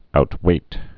(out-wāt)